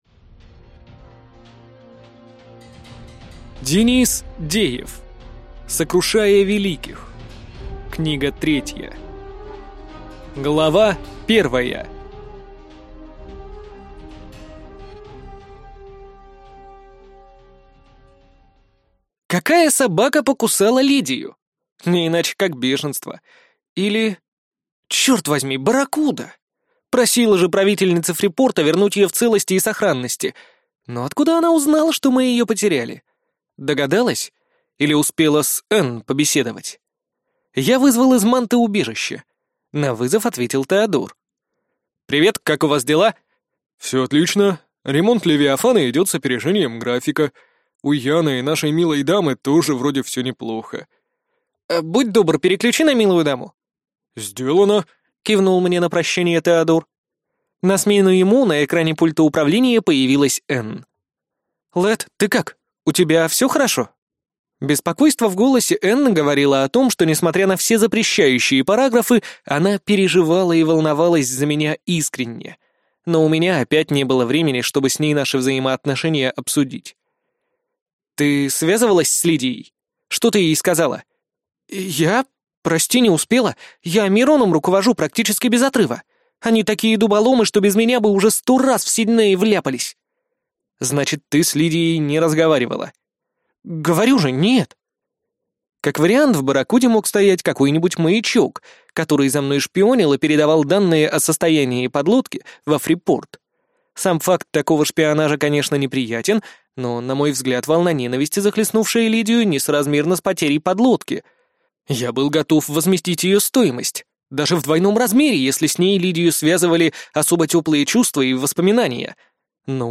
Аудиокнига Сокрушая великих. Книга 3 | Библиотека аудиокниг